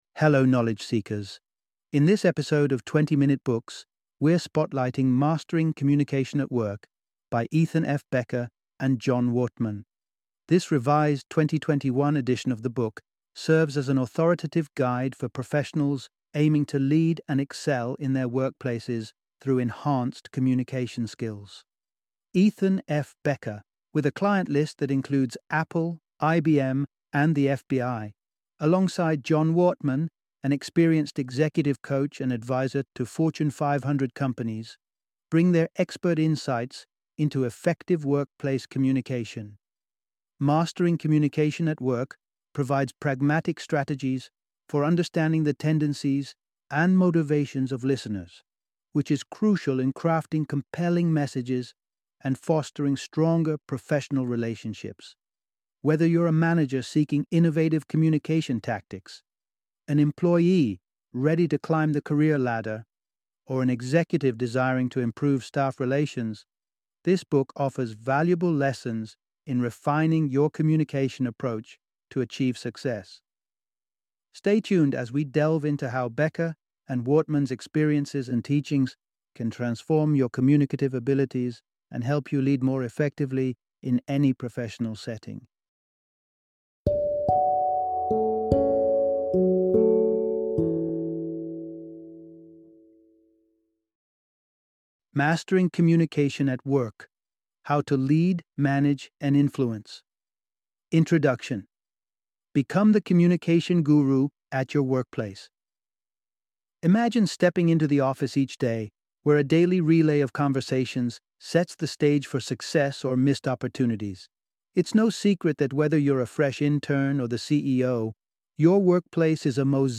Mastering Communication at Work - Audiobook Summary